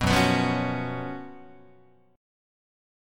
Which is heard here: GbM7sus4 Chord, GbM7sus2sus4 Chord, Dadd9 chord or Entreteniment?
GbM7sus4 Chord